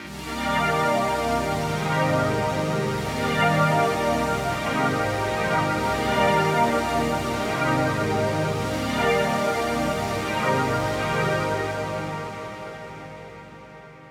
You can also use panning to increase the width of the patch for an extra widescreen effect. In this example, layers two and three are panned away from each other for a bigger feel.
Galaxias-mixing.wav